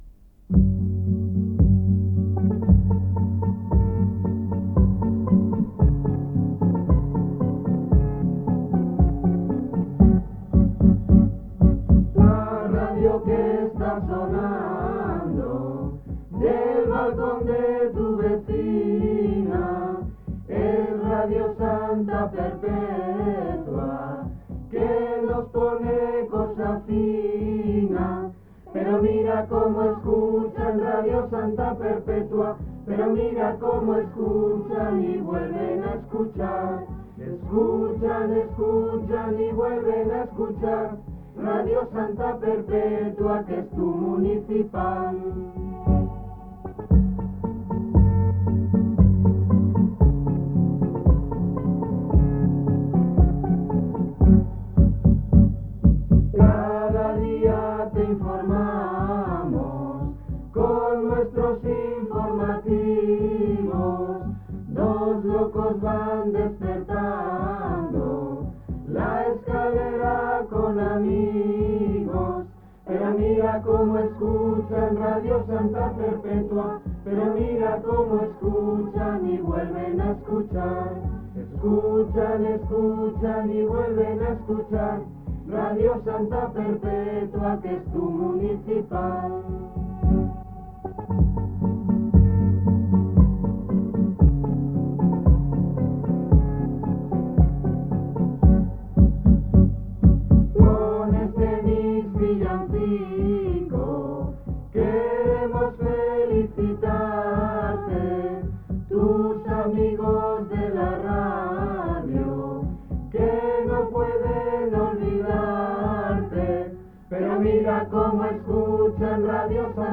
cantada pels integrants de l'emissora
FM
Fragment procedent d'unes bobines magnetofòniques